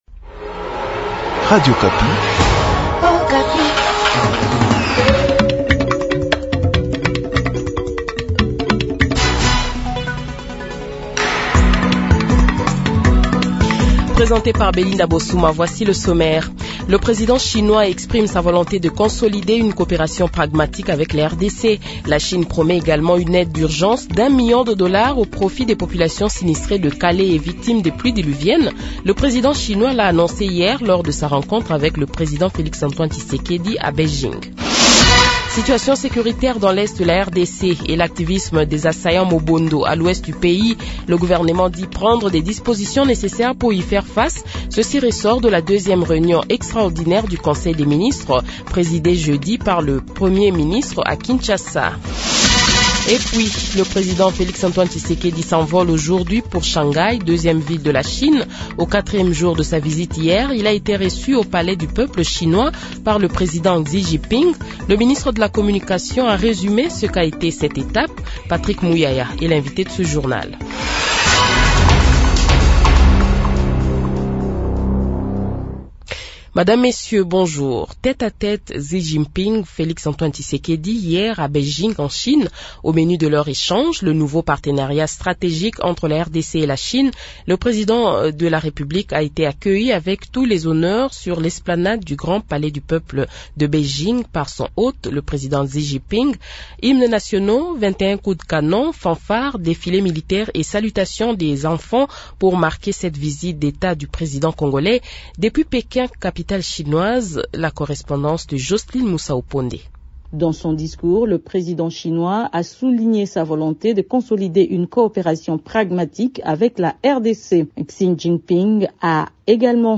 Journal Matin
invité Patrick Muyaya, ministre de la communication et des médias